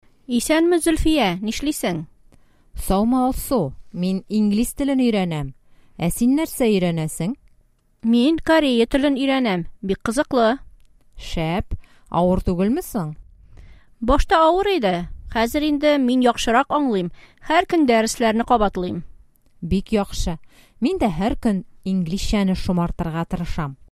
Диалог: Кафеда очрашу